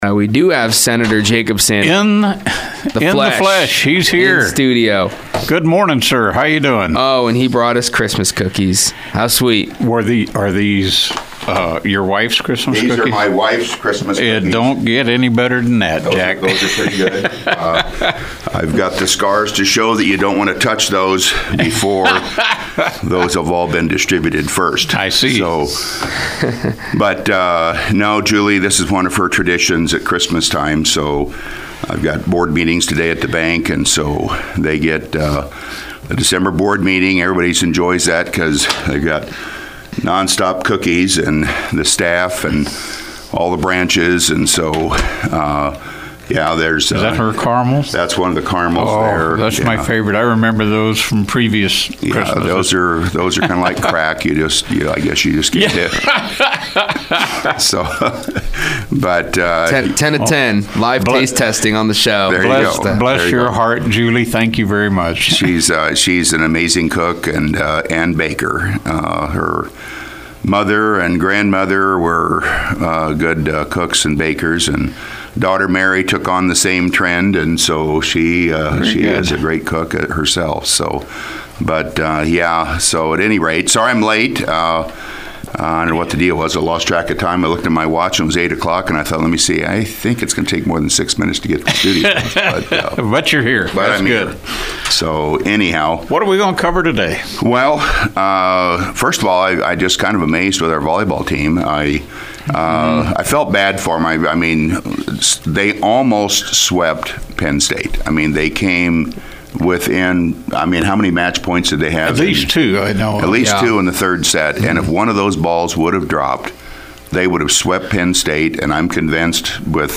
senator-jacobson-inteview.mp3